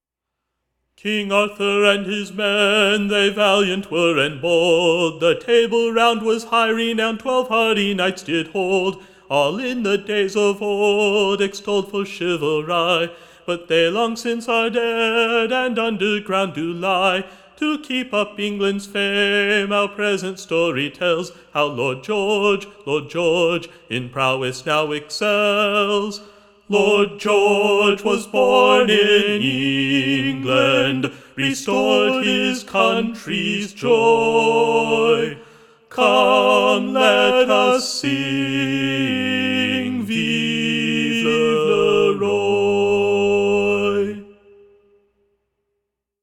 with choral harmony created by electronically laying his own voice under his own voice in the recording